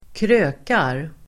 Uttal: [²kr'ö:kar]